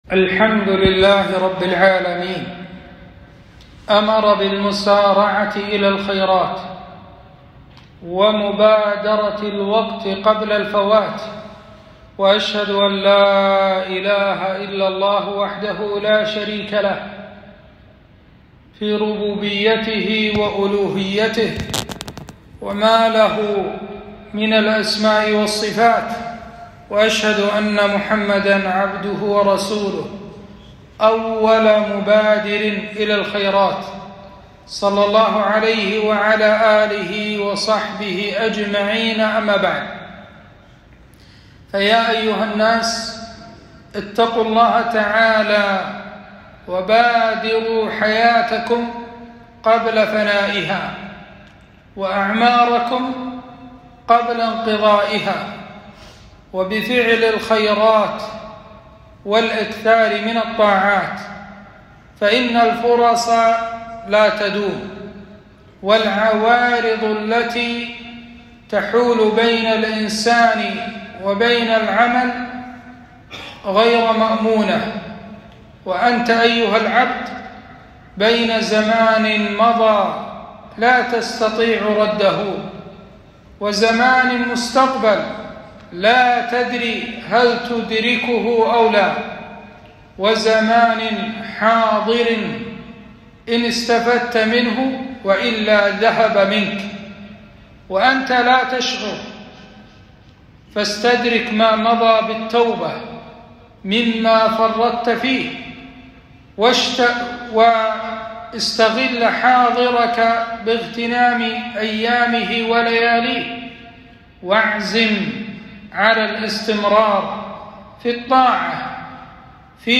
خطبة - المسارعة في الخيرات